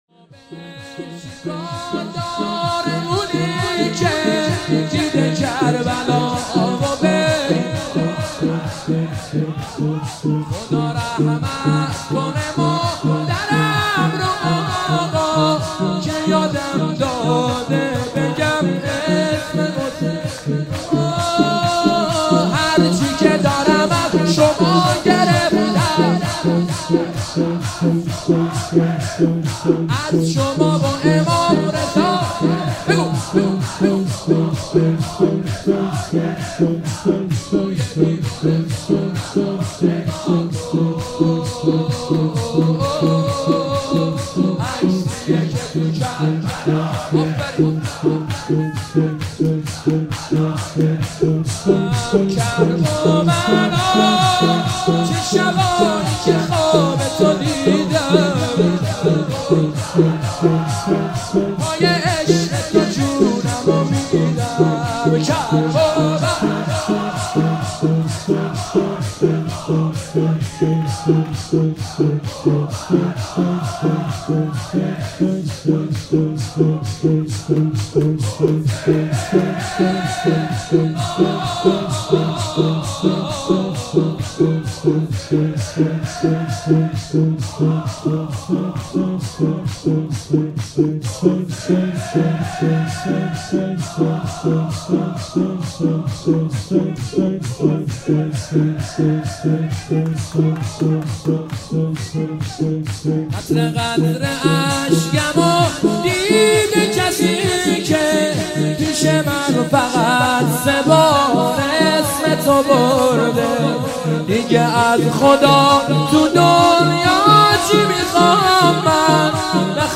مداحی ویژه پیاده روی اربعین